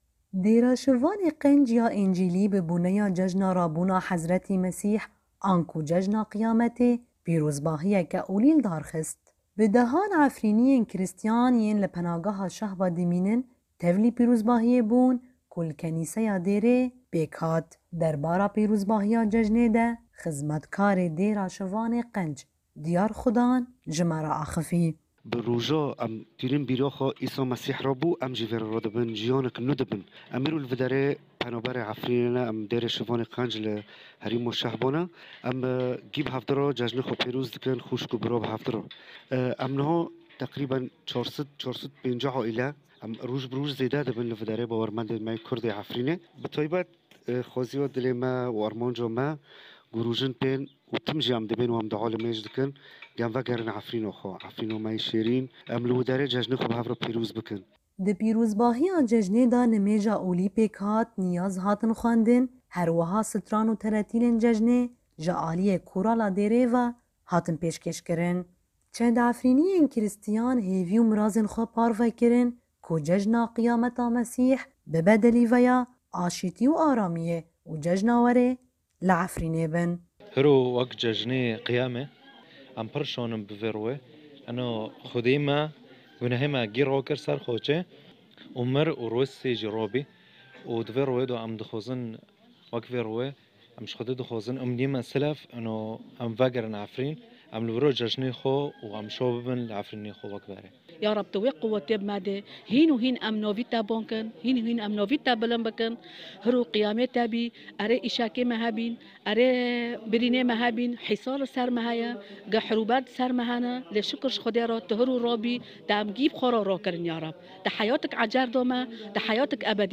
Bi dehan Efrînîyên Krîstîyan yên li penageha Şehba dimînin, tevlî pîrozbahîyê bûn ku li kenîseya Dêra Şivanê Qenc pêk hat.
Di pîrozbahîya cejnê de nimêj û nîyazên olî hatin xwendin herwiha stran û tertîlên cejnê ji alîyê koroya dêrê ve hatin pêşkêş kirin.